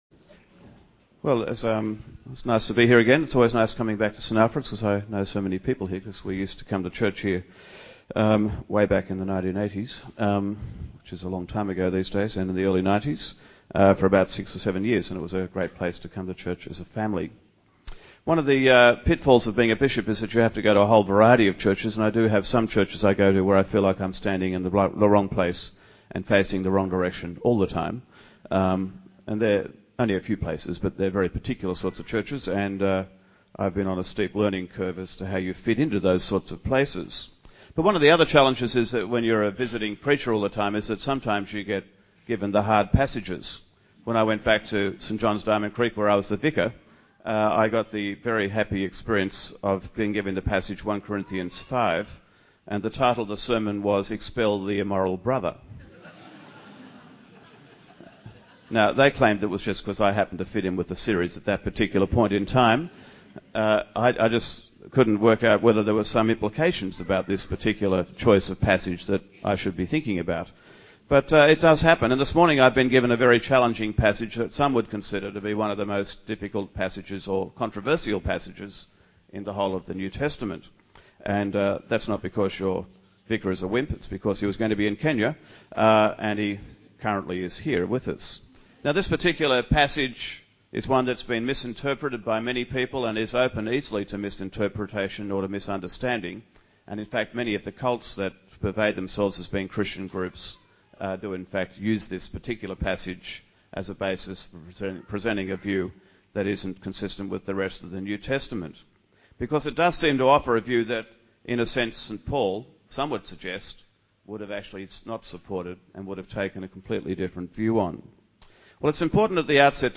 In this sermon, Bishop Stephen Hale speaks on the theme of 'How To Have Real Faith' as part of the series 'James'.